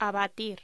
Locución: Abatir